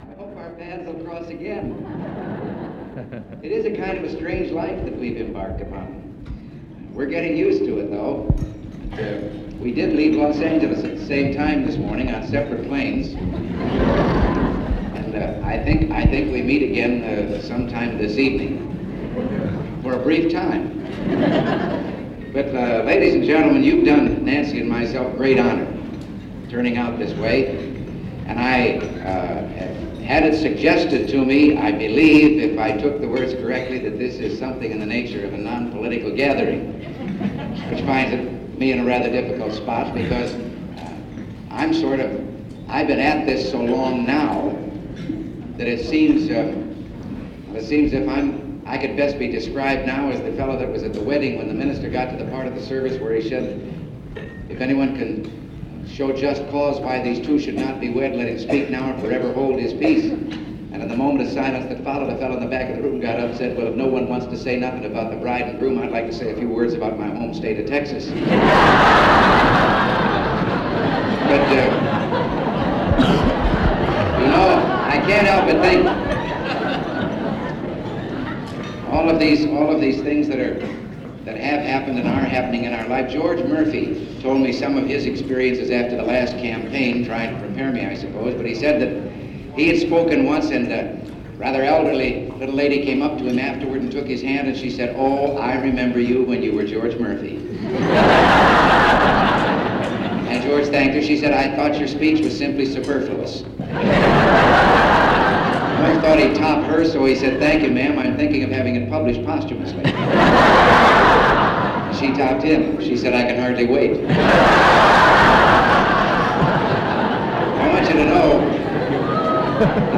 Ronald Reagan Speech and question and answer with press at Santa Clara Chamber of Commerce
MP3 Audio file Tape Number CD-1 COMP09 Location Santa Clara, California Tape Length 32:35